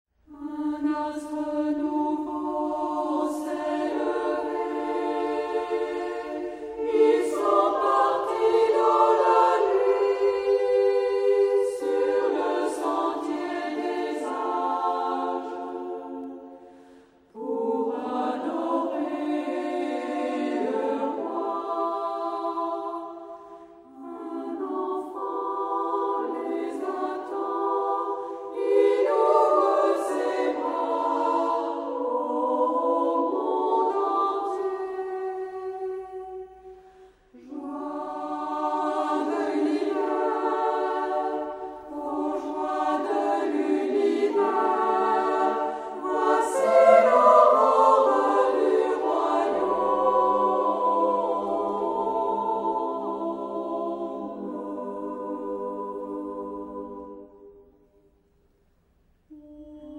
Genre-Style-Forme : Sacré ; Cantique
Caractère de la pièce : vivant
Solistes : Soprano (1) OU Ténor (1)  (1 soliste(s))
Instrumentation : Orgue (ad lib.) OU Quatuor de flûtes
Tonalité : ré mode de ré